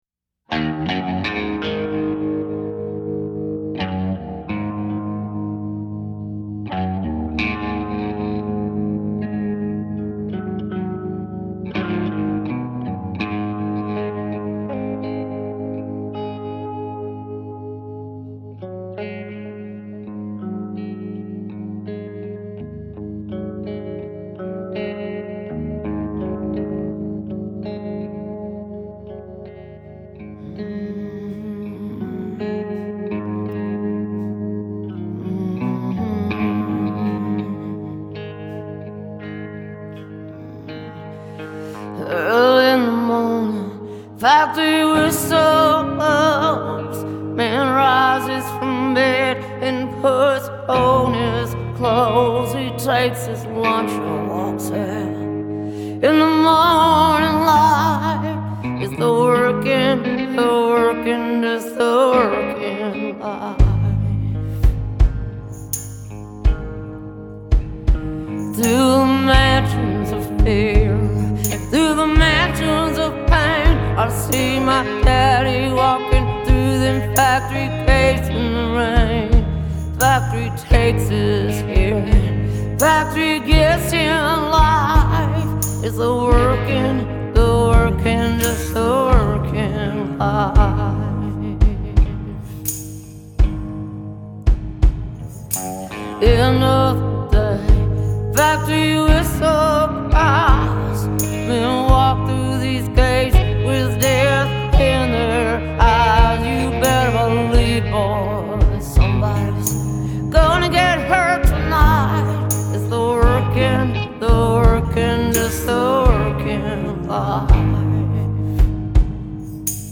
It’s an elegant cover.